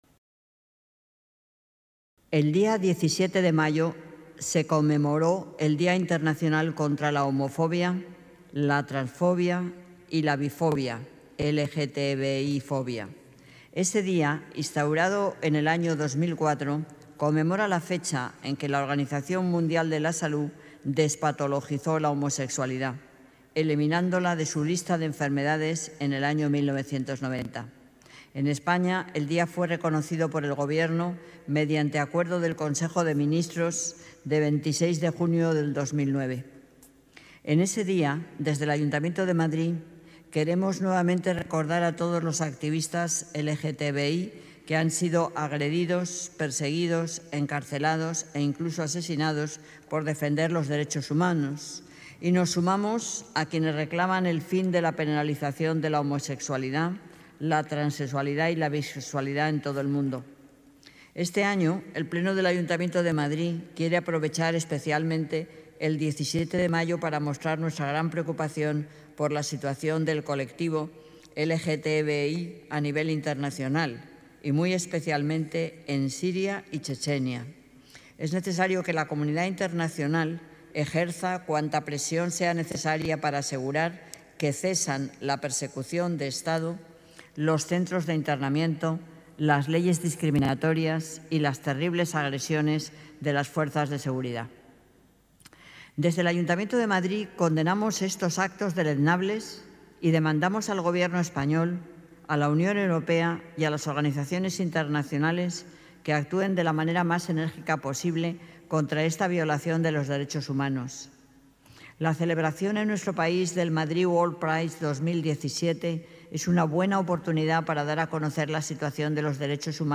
Nueva ventana:Manuela Carmena lee la Declaración Institucional contra la LBTBIfobia